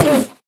Minecraft Version Minecraft Version snapshot Latest Release | Latest Snapshot snapshot / assets / minecraft / sounds / mob / endermen / hit1.ogg Compare With Compare With Latest Release | Latest Snapshot